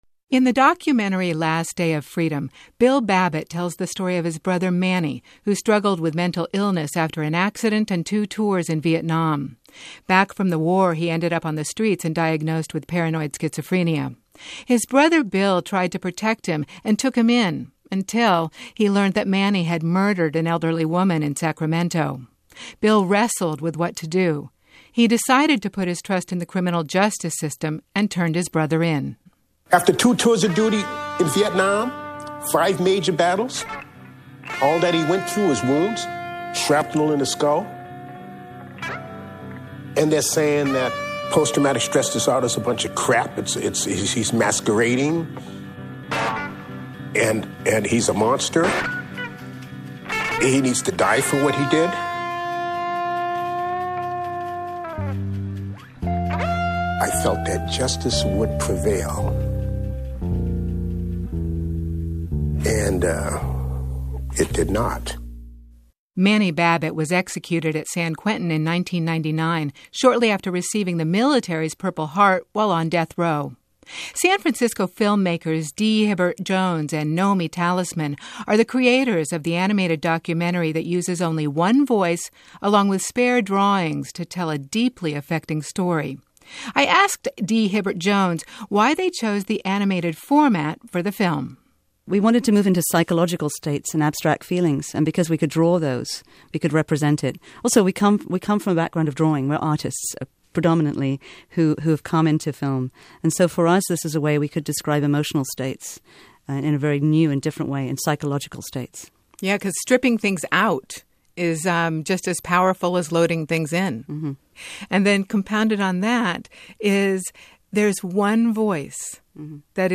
Filmmakers interview for the California Report Magazine, NPR